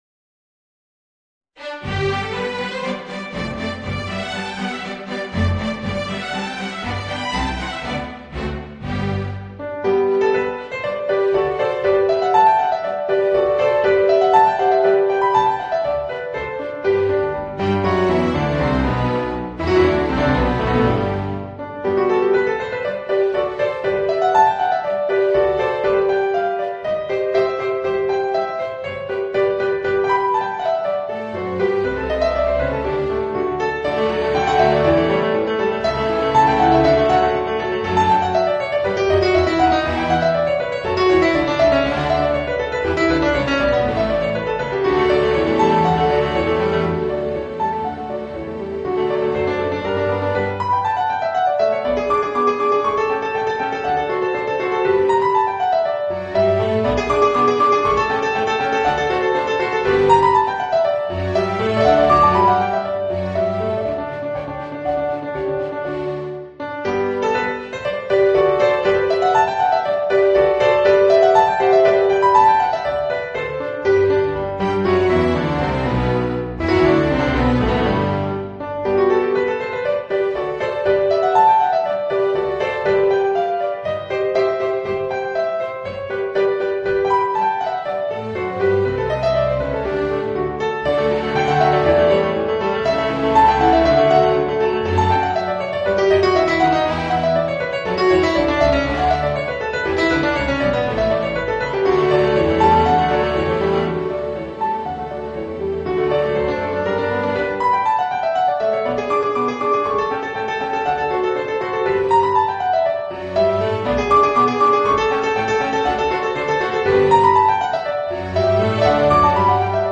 Voicing: Piano and String Orchestra